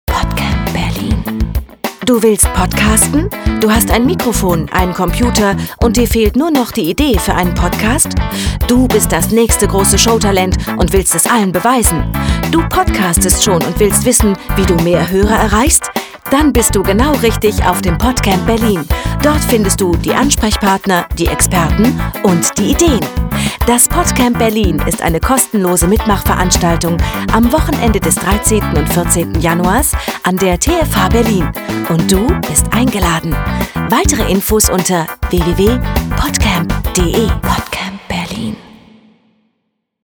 Die Sound-Trailer fuer das PodCamp sind fertig.
Spot 1 – Serioese Variante
Vielen Dank an unseren Sponsor dynamicaudio für das grossartige Sounddesign in den sehr gelungenen Spots.
podcamp_spot_1_serious.mp3